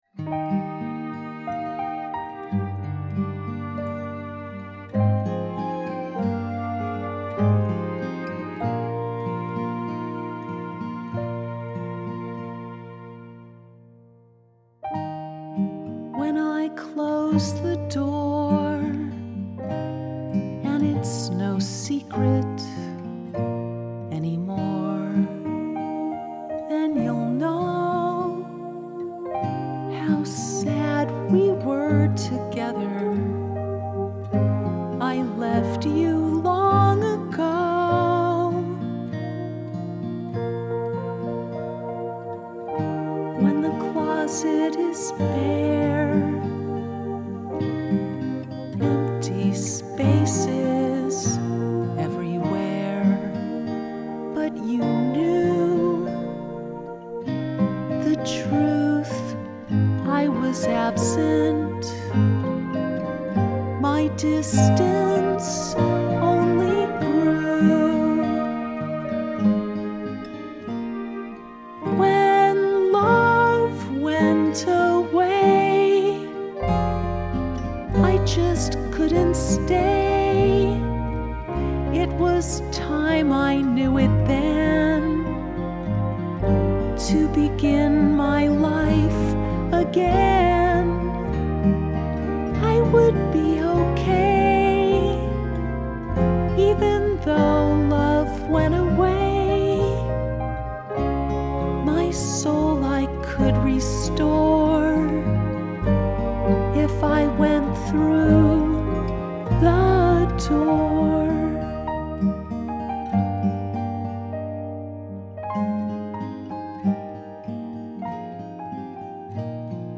Vocal Mix
the-door-vocal-mix-10-25.mp3